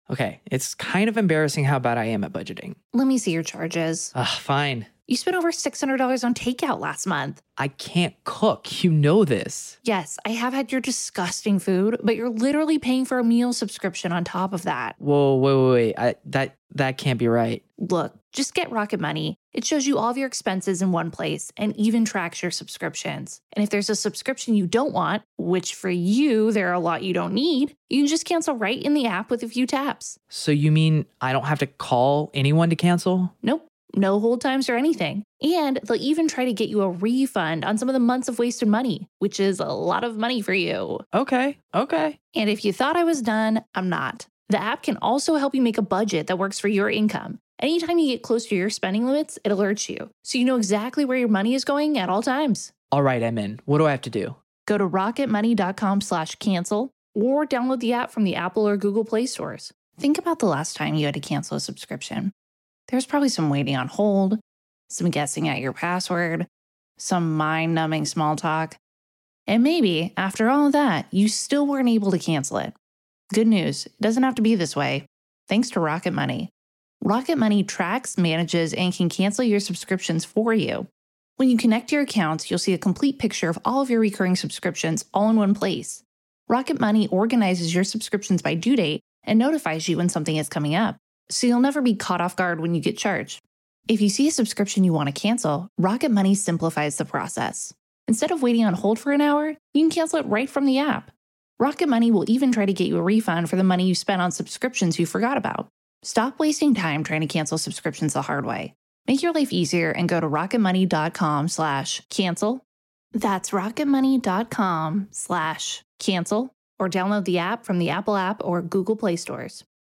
live Q&A